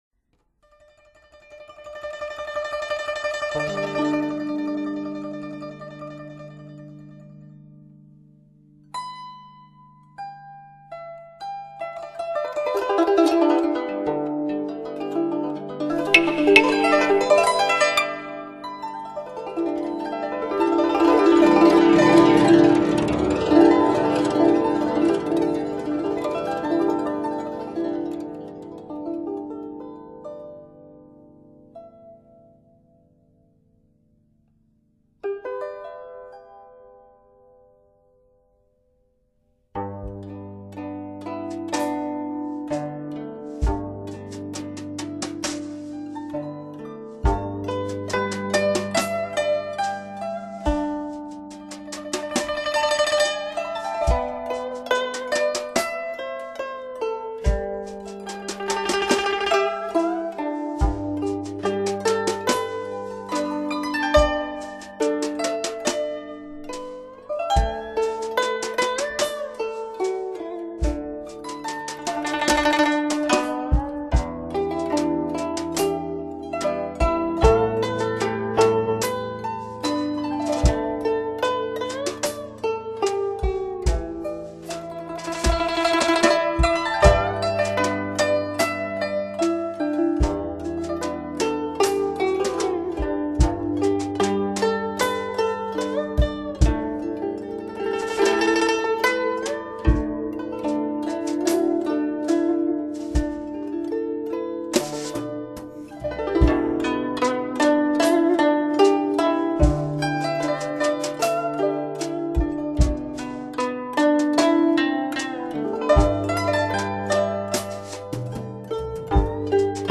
一次跨国界的声音革命，中西音乐的对话，展现东方音乐的魅力。